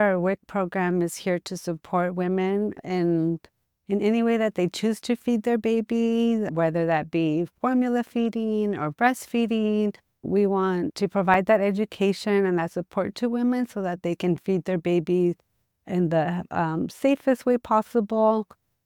Testimonial 5 - WIC staff